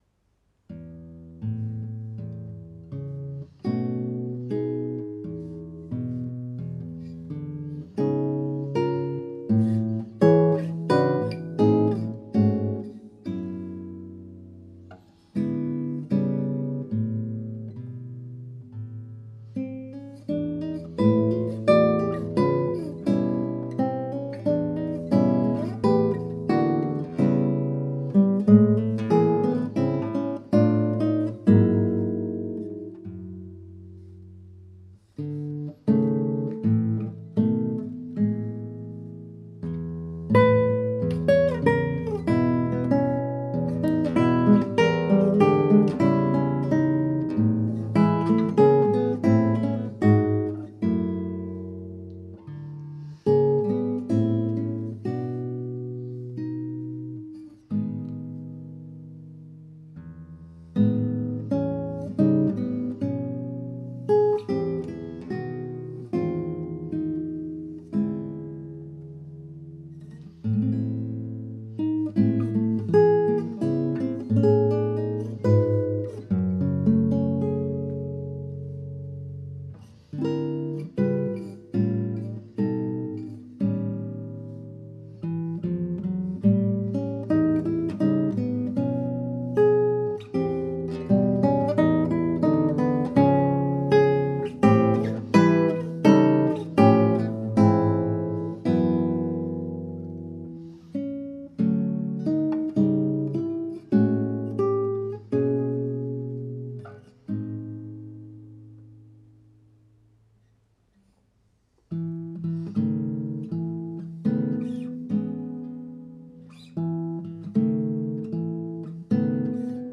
Passacaglia on Happy Birthday (for guitar, 2022) SCORE |